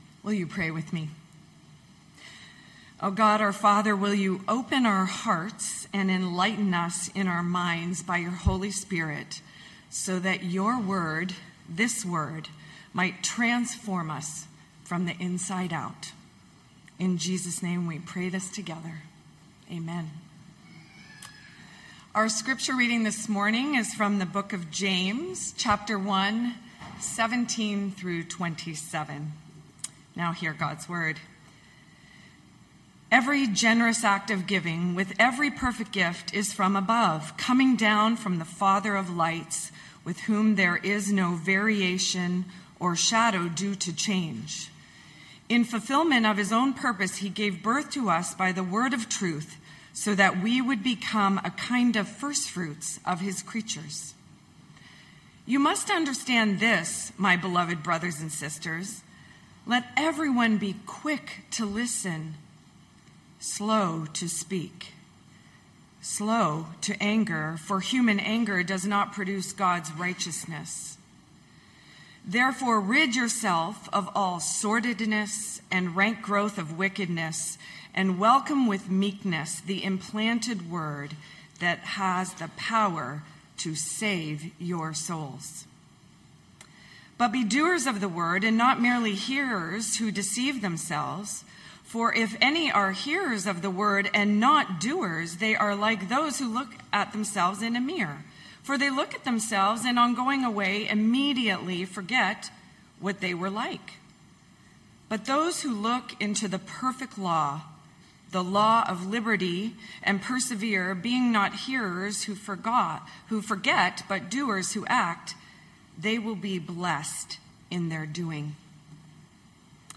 Knox Pasadena Sermons Gifts from Above Jul 06 2025 | 00:24:37 1x 00:00 / 00:24:37 Subscribe Share Spotify RSS Feed Share Link Embed